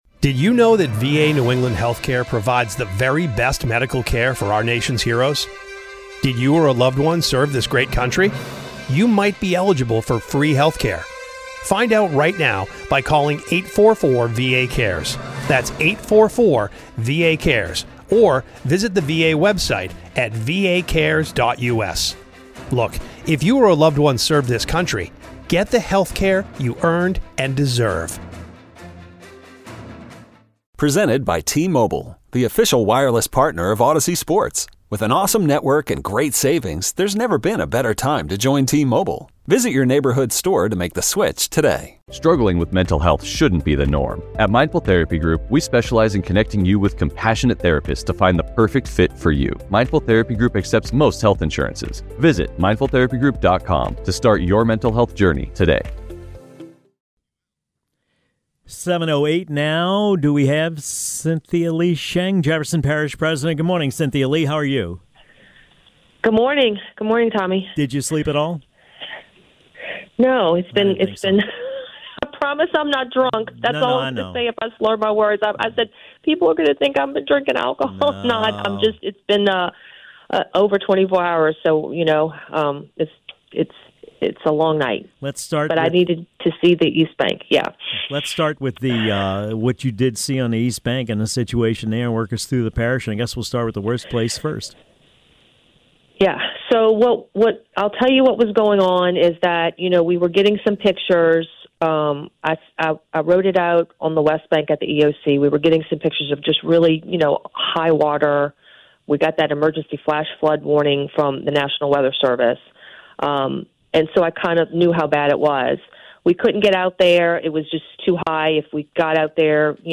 talks to Jefferson Parish President Cynthia Lee Sheng about the flooding caused by Hurricane Francine's rainfall.